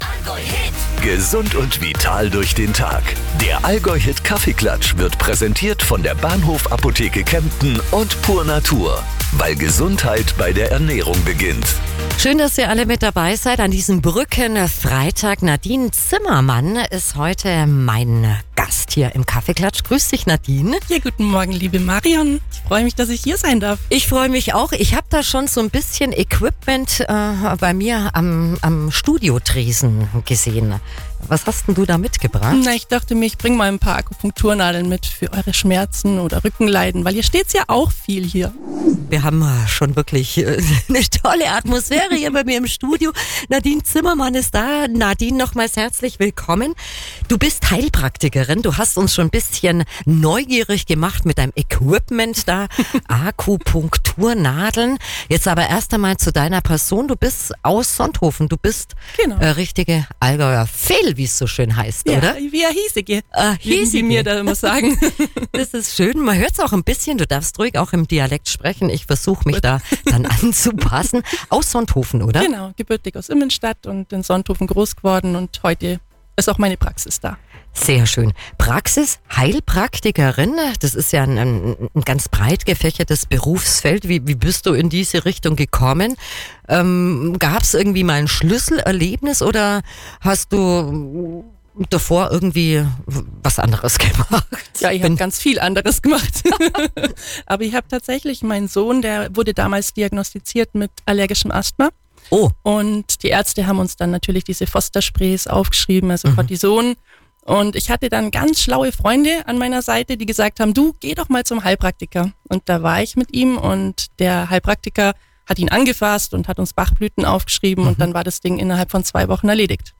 Talk